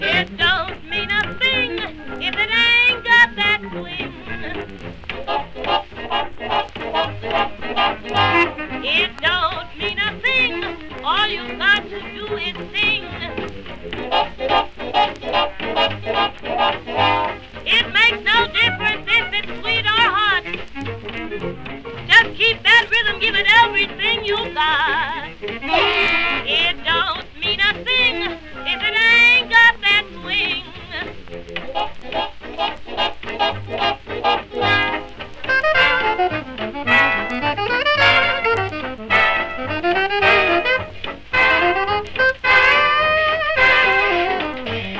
Jazz, Big Band, Vocal　USA　12inchレコード　33rpm　Mono